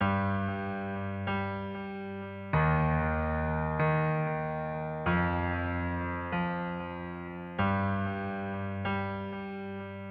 标签： 95 bpm Weird Loops Piano Loops 1.70 MB wav Key : Unknown
声道立体声